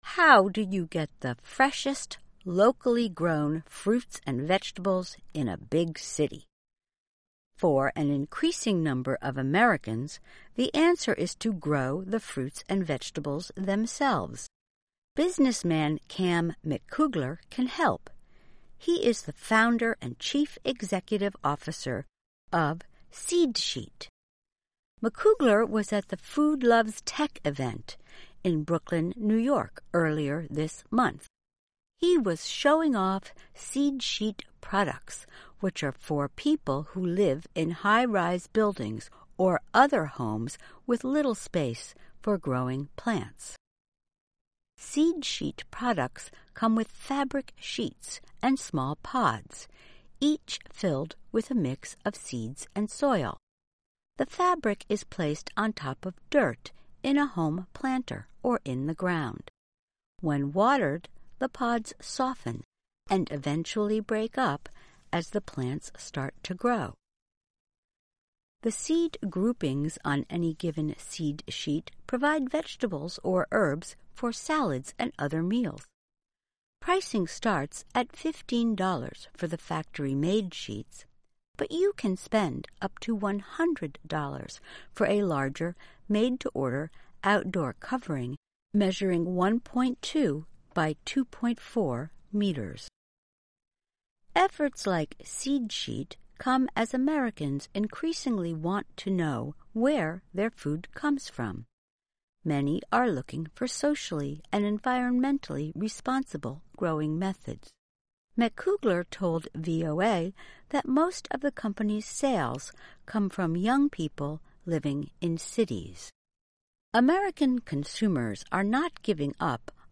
慢速英语:美国企业为城市提供农业技术